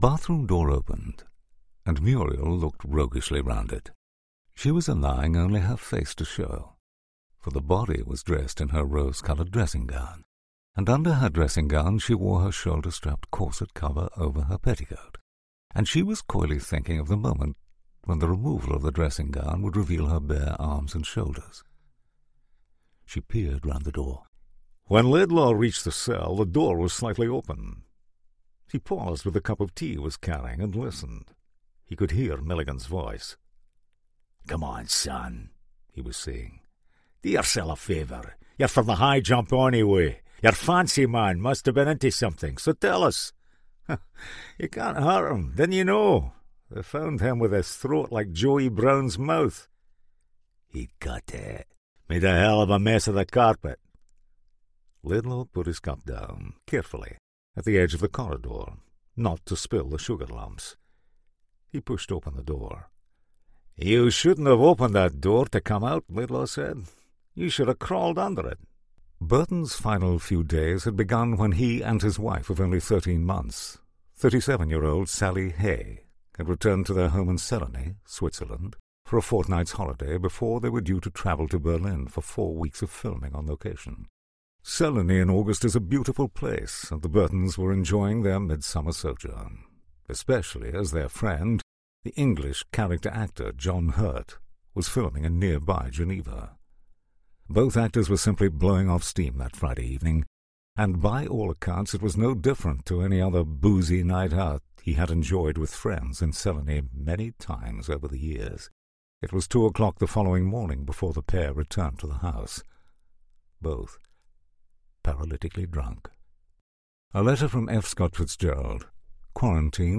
He has a huge range of character voices and a wide range of accents suitable for gaming and animation.
• Male
• Standard English R P